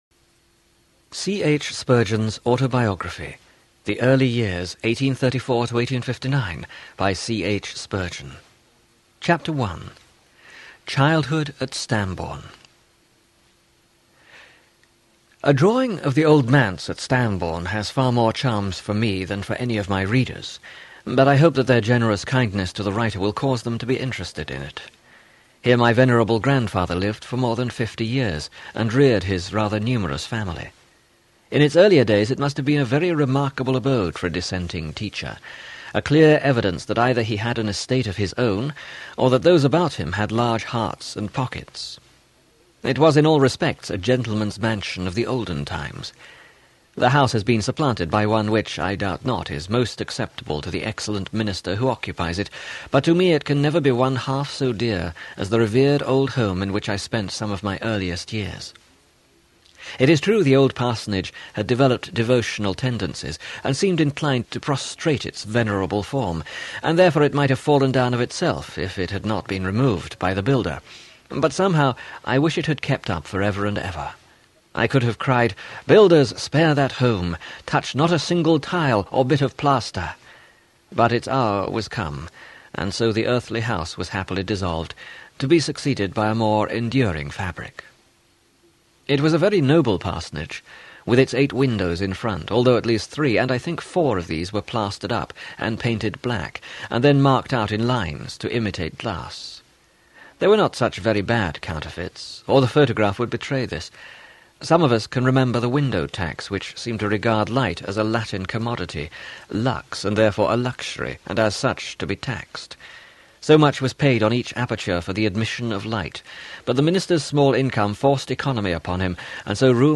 CH Spurgeon Autobiography: The Early Years Audiobook
20.4 Hrs. – Unabridged